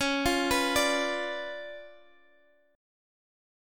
Db7b9 Chord